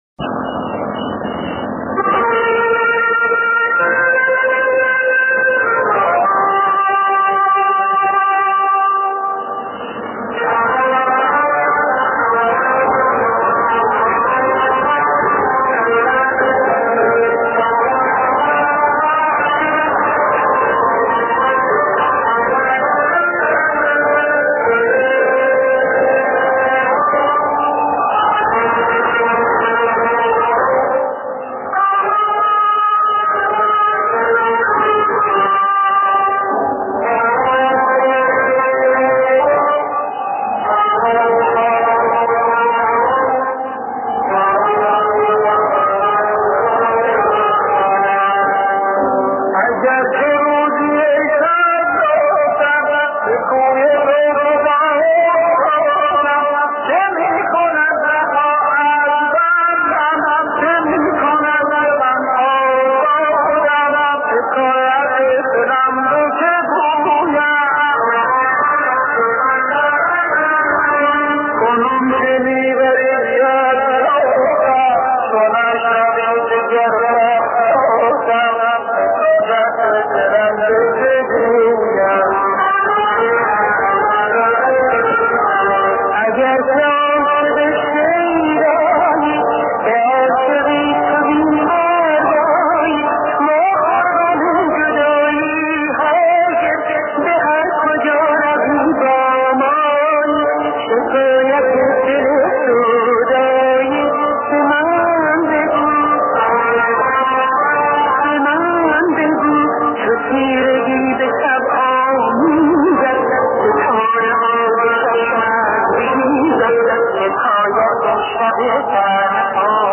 آهنگ سنتی موسیقی دستگاهی موسیقی پاپ موسیقی کوچه بازاری